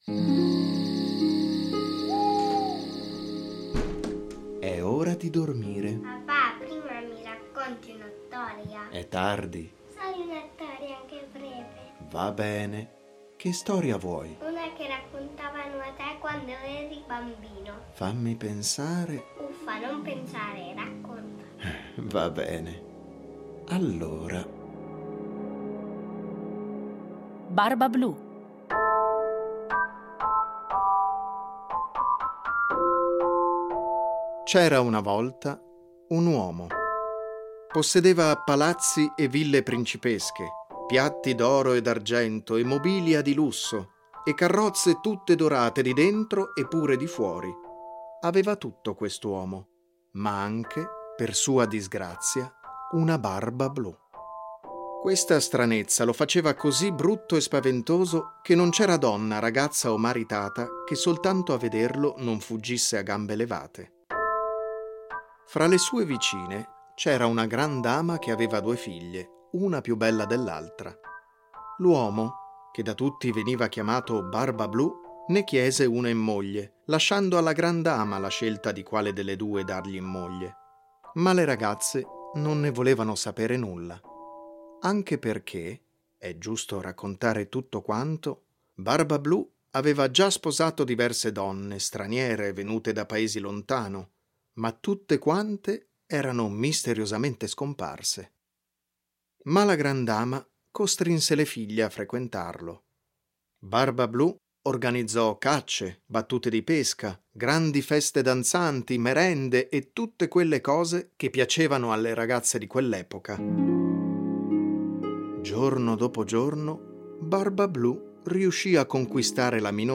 A partire dai testi originali un adattamento radiofonico per far vivere ai bambini storie conosciute, ma un po'dimenticate